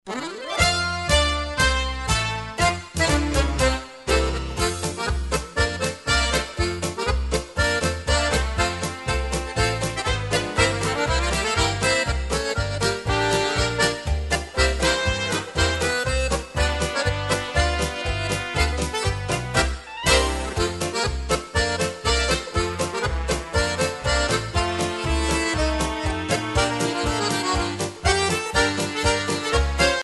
Non Stop Medleys Including: